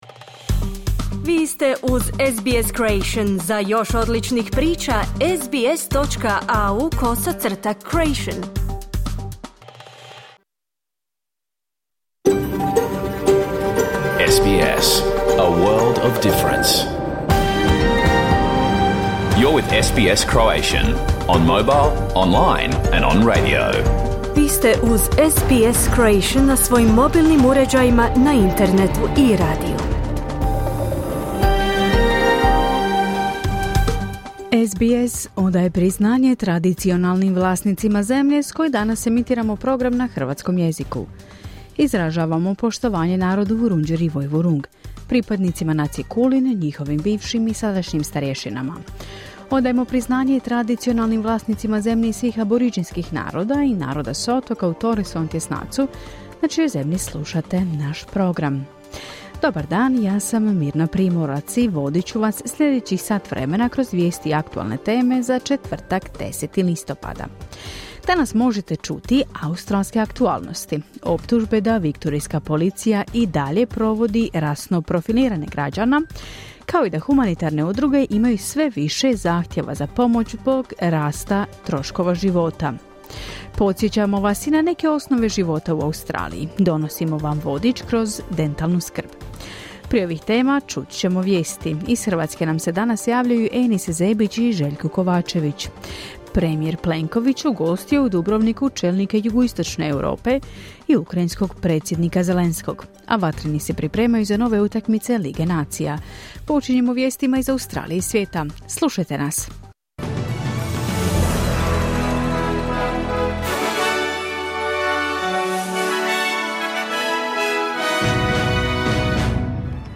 Vijesti i aktualne teme iz Australije, Hrvatske i ostatka svijeta. Program je emitiran uživo na radiju SBS1 u četvrtak, 10. listopada, u 11 sati po istočnoaustralskom vremenu.